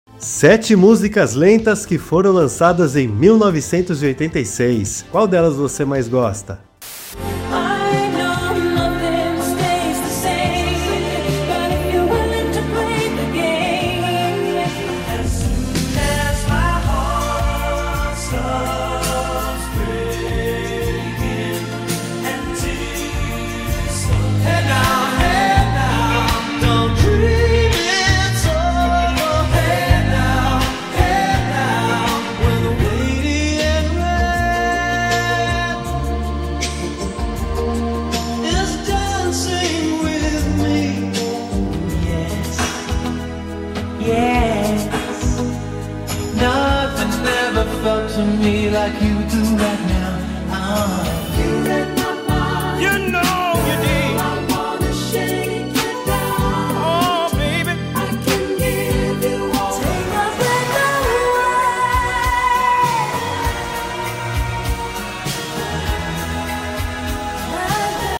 mais lentas, românticas e reflexivas